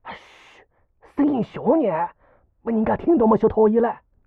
c02_6偷听对话_癞子_1_fx.wav